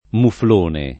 muflone [ mufl 1 ne ]